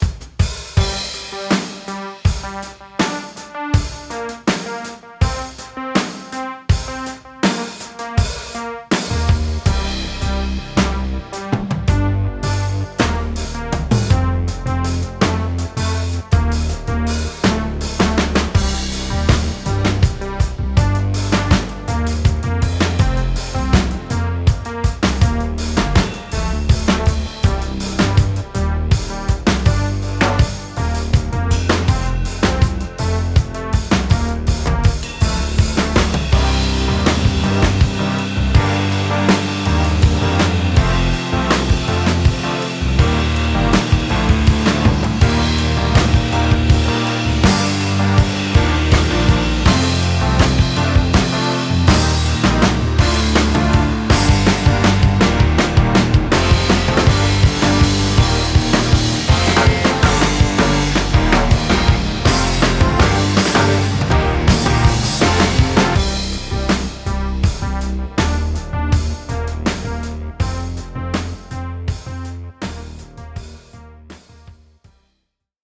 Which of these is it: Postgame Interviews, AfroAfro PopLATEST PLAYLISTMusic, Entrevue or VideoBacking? VideoBacking